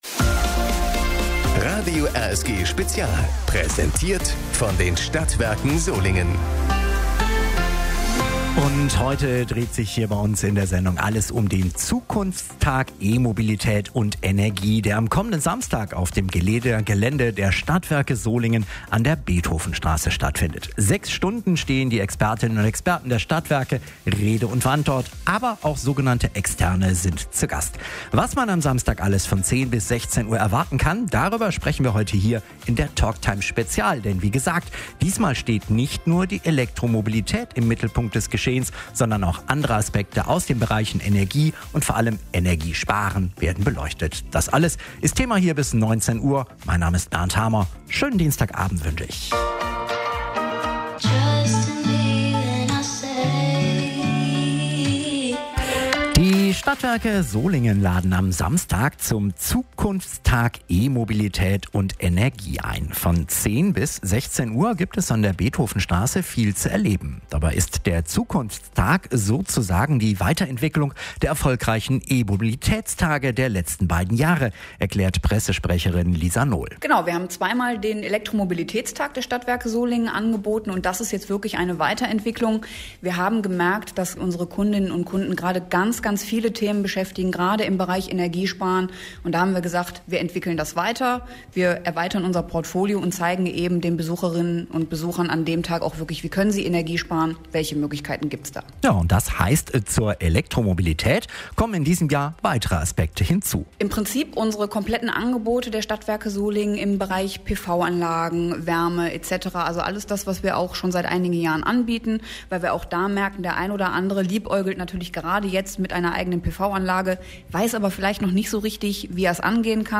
Am 20. August dreht sich bei den Solinger Stadtwerken alles ums Energiesparen, alternative Energiequellen und Elektromobilität. Radio RSG ist beim Zukunftstag live dabei.